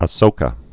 (ə-sōkə, -shō-) Known as "the Great."